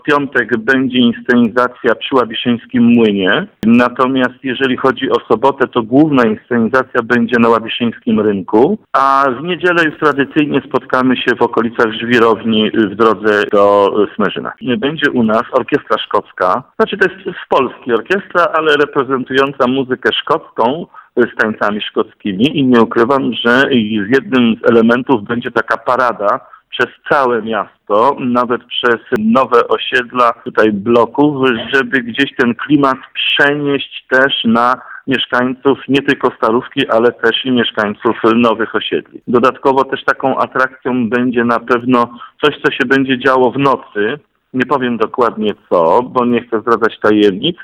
Mówił ponownie burmistrz Łabiszyna.